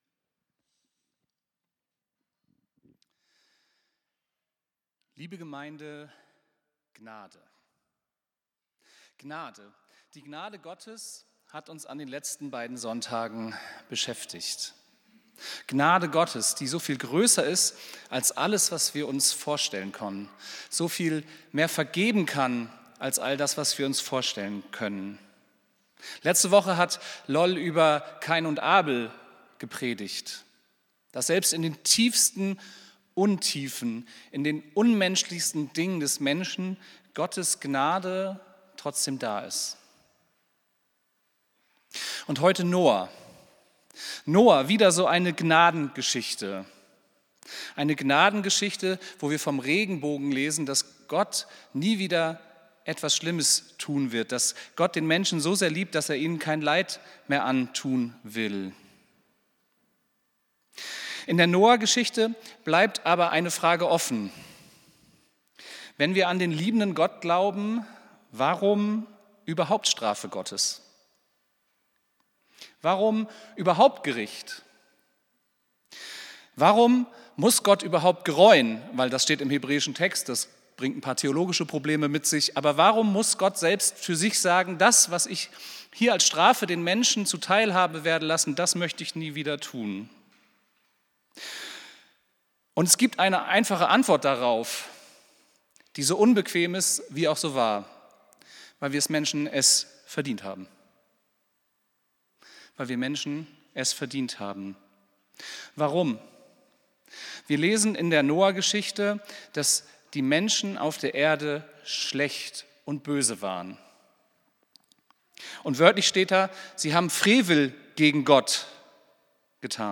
Predigt vom 21.09.2025